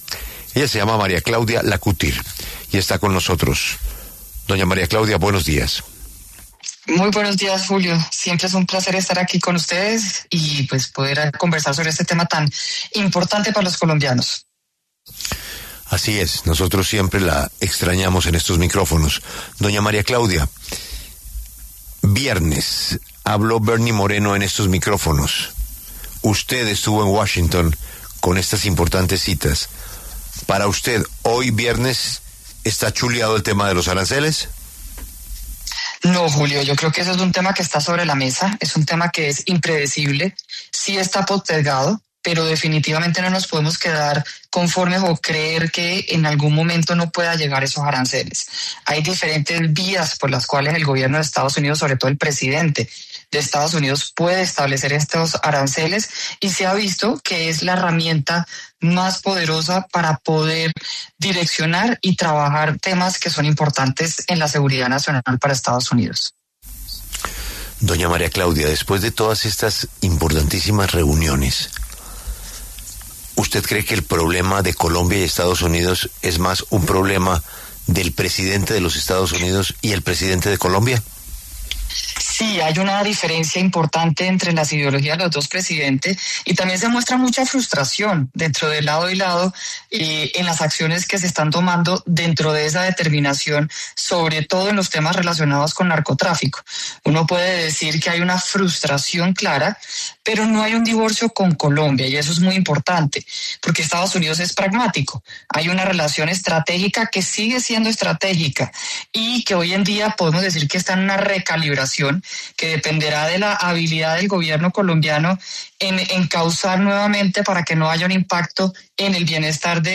María Claudia Lacouture, presidenta de AmCham, conversó con La W sobre las tensiones que se se han presentado entre los Gobiernos de Donald Trump y Gustavo Petro.